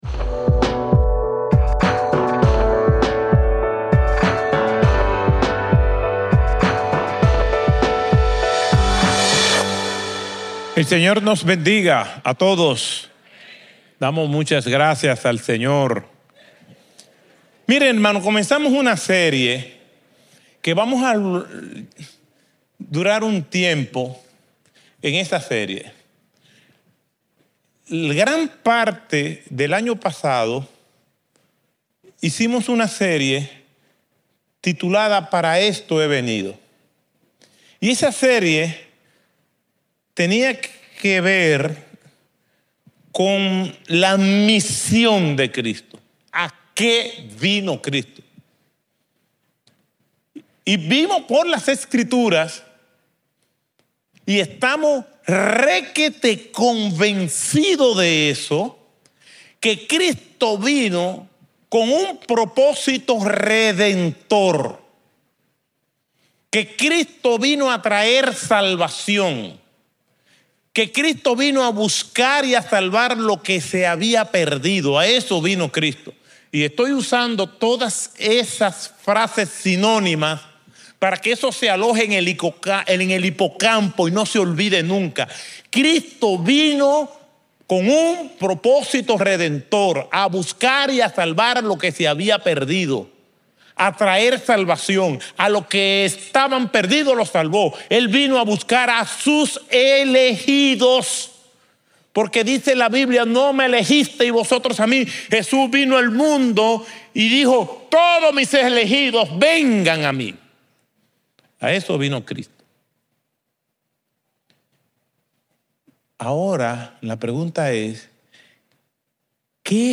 Un mensaje de la serie "Ser para hacer."